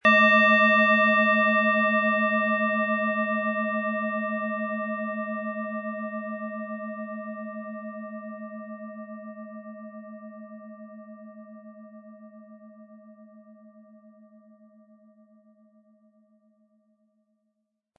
SchalenformBihar
MaterialBronze